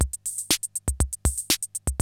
CR-68 LOOPS3 1.wav